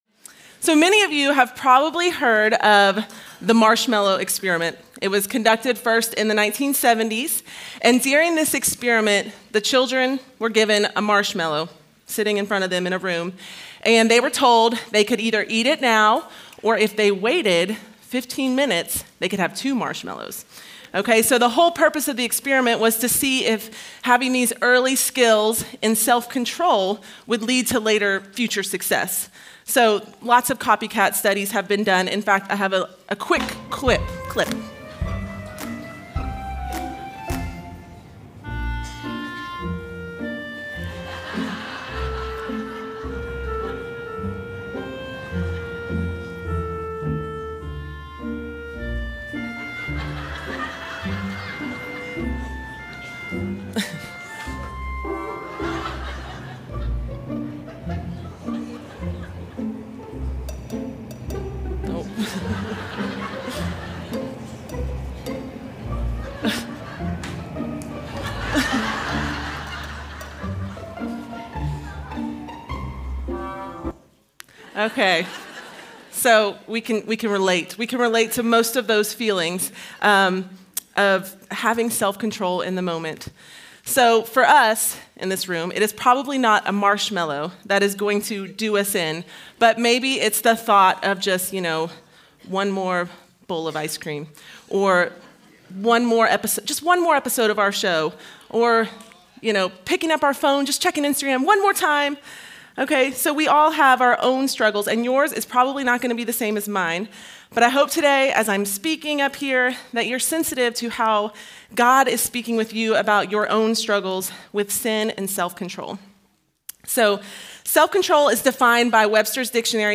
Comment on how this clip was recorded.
Women's Gathering